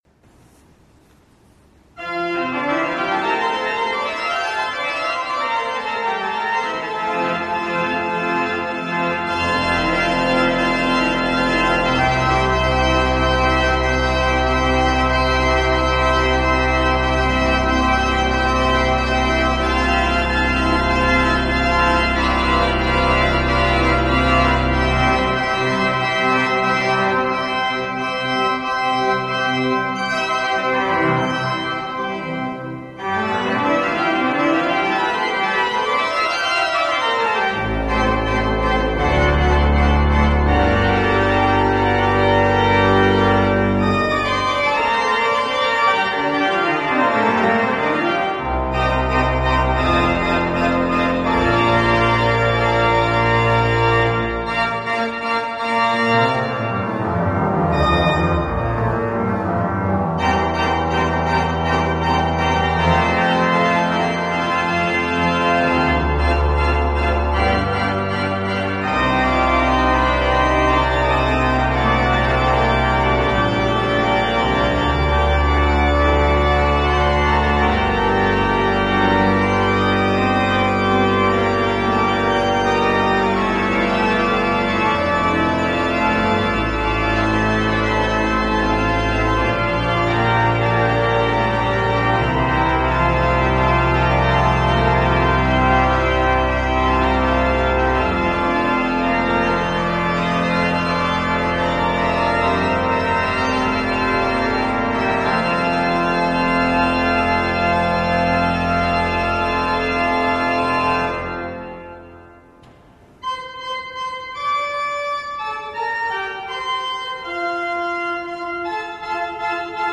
played on the Binn's Organ at Rochdale Town Hall